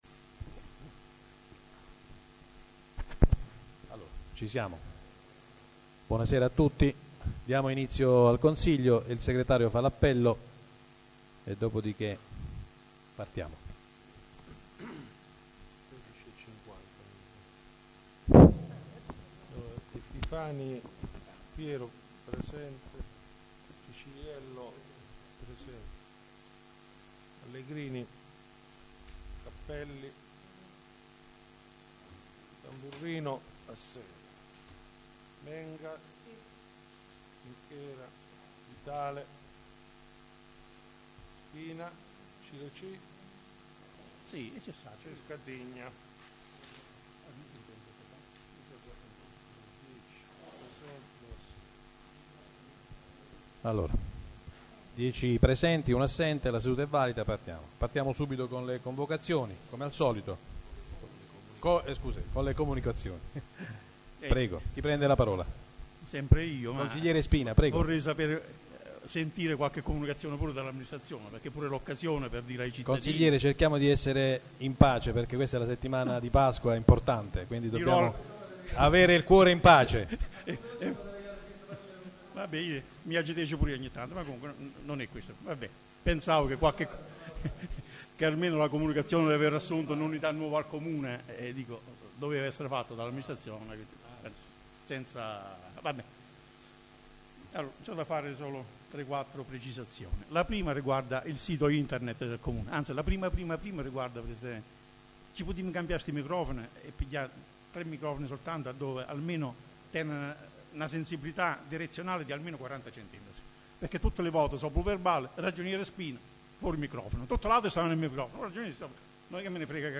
Un ringraziamento al Presidente del Consiglio Comunale Pino Trinchera per averci dato la possibilità di registrare direttamente dal mixer, il che ha migliorato leggermente la qualità della registrazione audio.